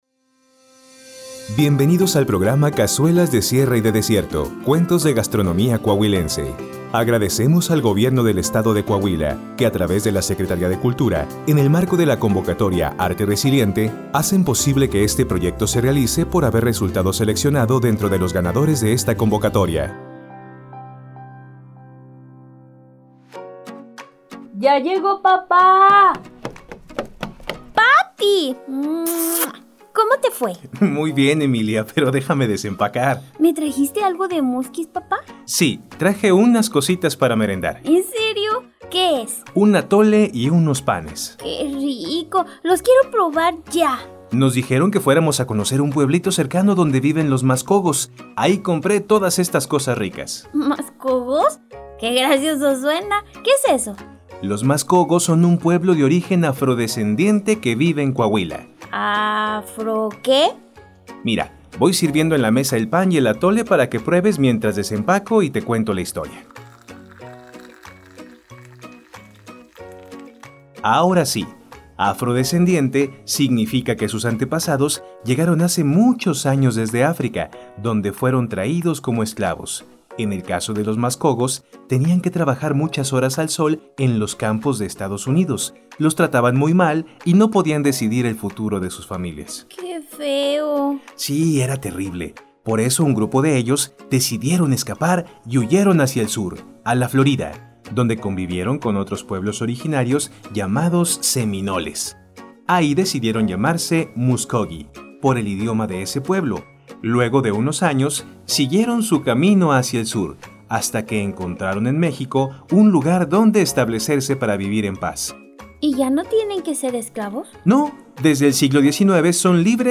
Audiocuentos de gastronomía coahuilense